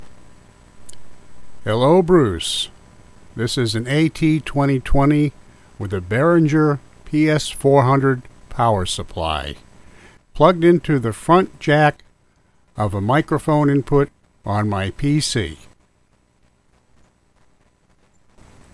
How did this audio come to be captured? I attached a short mp3 using an AT2020 to a Behringer PS400 phantom supply through an XVM-115 cable to the PC mic jack recorded with Audacity. Don't have high expectations for sound quality, but worth a try. AT2020 PS400 to PC.mp3